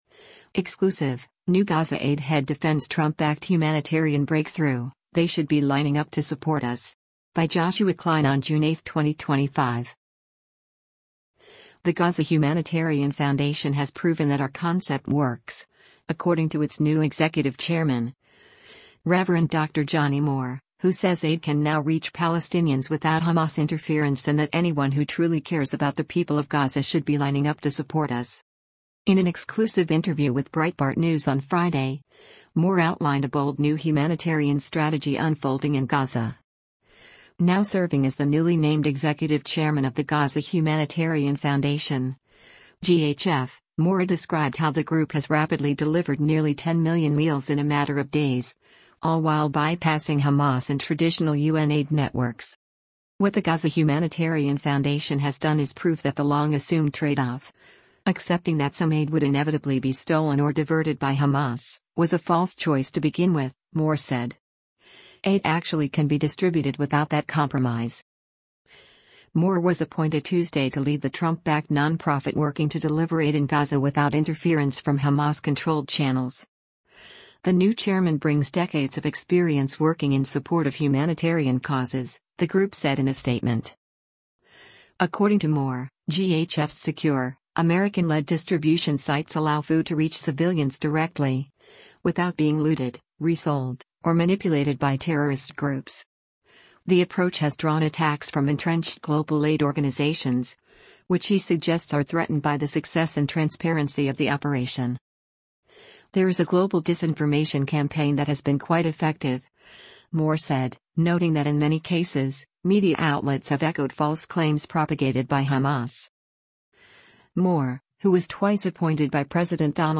In an exclusive interview with Breitbart News on Friday, Moore outlined a bold new humanitarian strategy unfolding in Gaza.